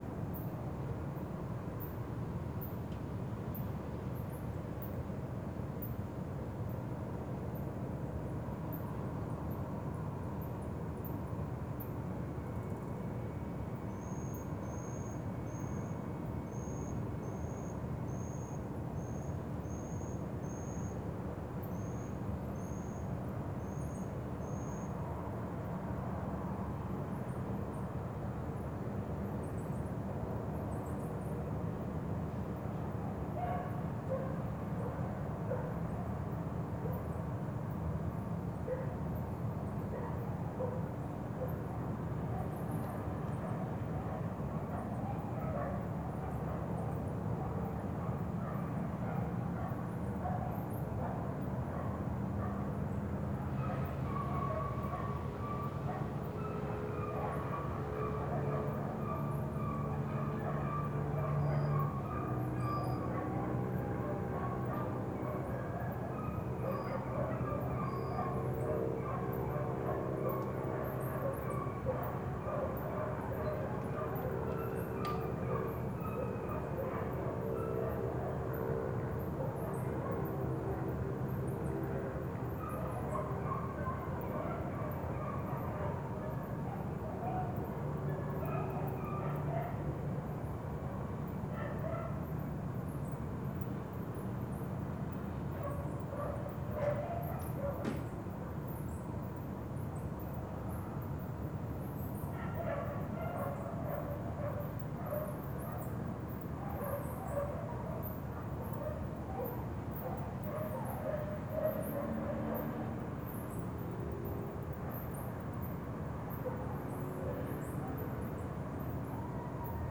Ambiente residencial de manhã cidade do interior vozes e periquitos
Alto Paraíso de Goiás Surround 5.1